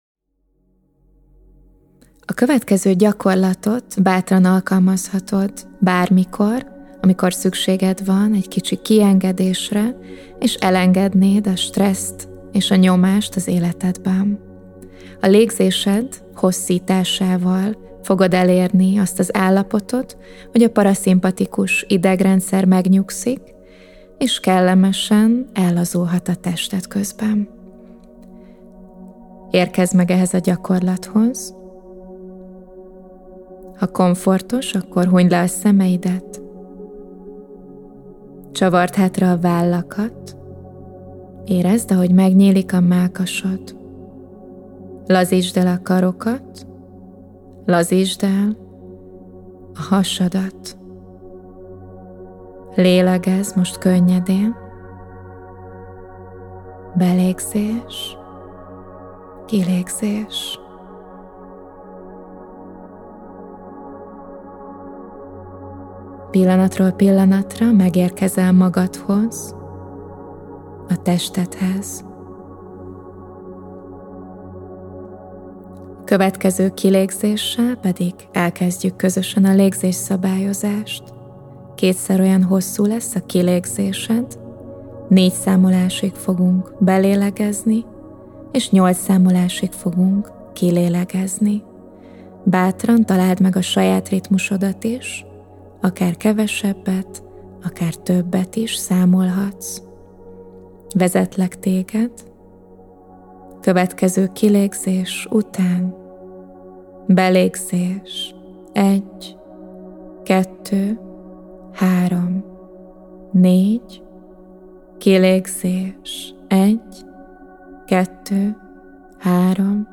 Stresszkezeles-MNDFL-Legzes-szabalyozas-stresszuzes-4-8-.mp3